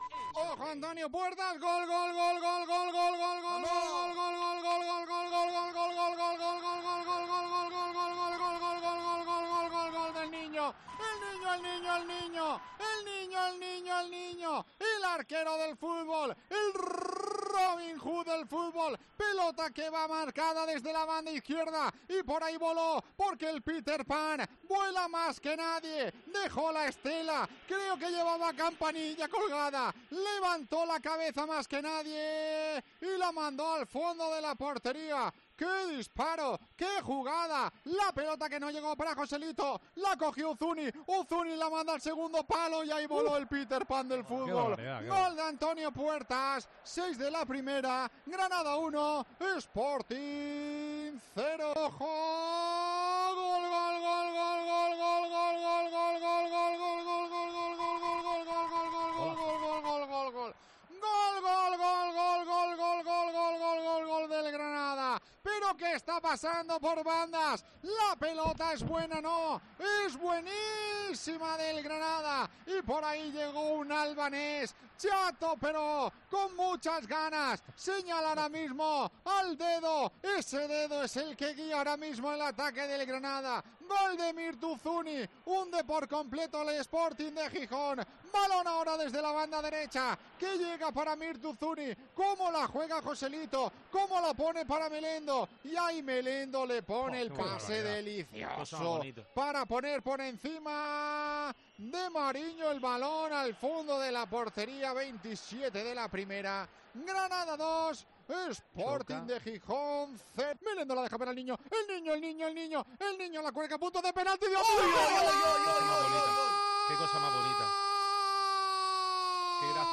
Escucha los goles del Granada CF 5-0 Sporting con la narración de COPE Granada